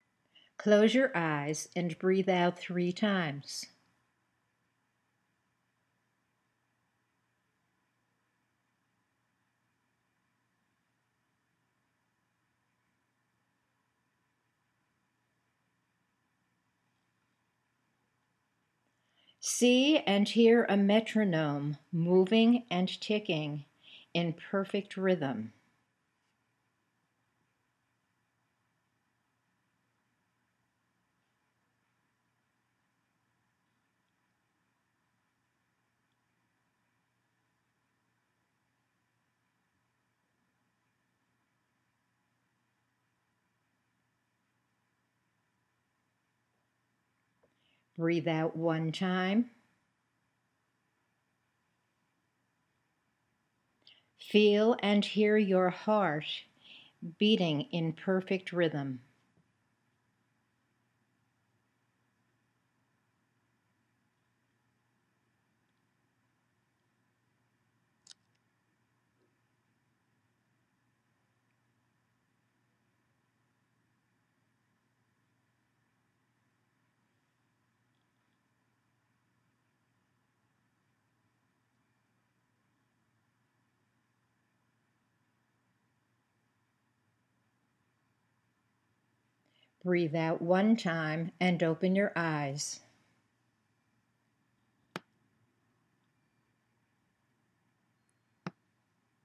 There is quiet space on the audio for your Imagery to emerge, followed by “breathe out one time and open your eyes, which signals the end of the exercise.
A Heart in Perfect Rhythm Imagery Narrative